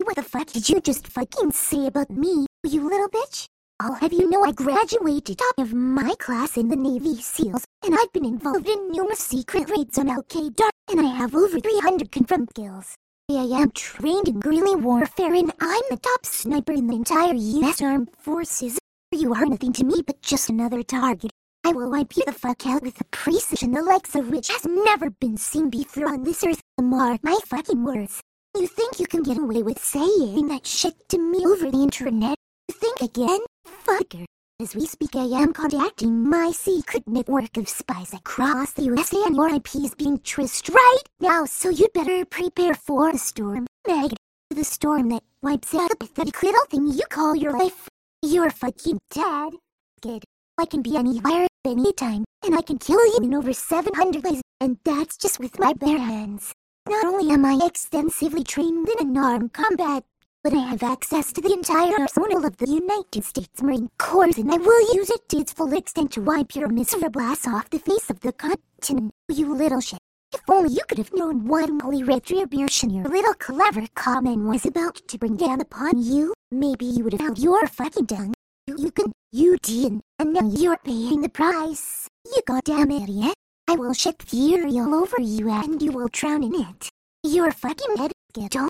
This exists! It's called "unit selection", and was an early method for generating high quality speech before everything became statistical.
But I still like this technique because it feels like a YTPMV shitpost (same technique, after all), but also has moments where it sounds more natural than any TTS because it uses the real samples.